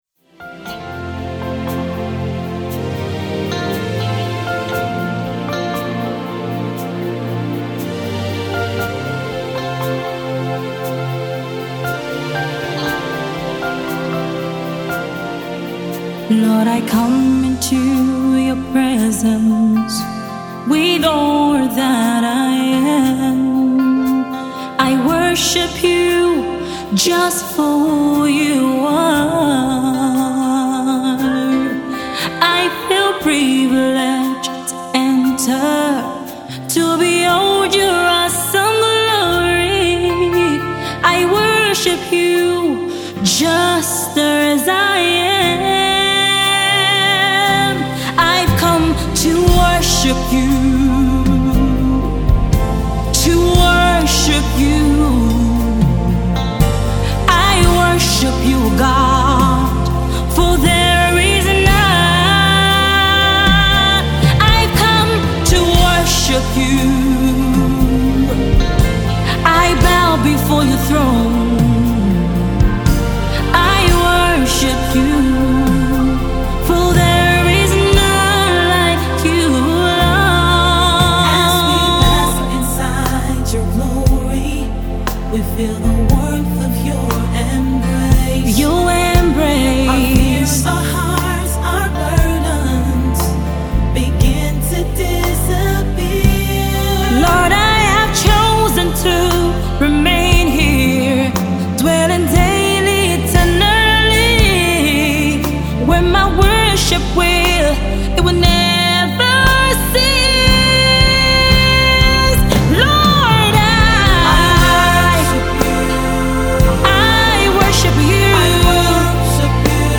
Enjoy this power packed single and be blessed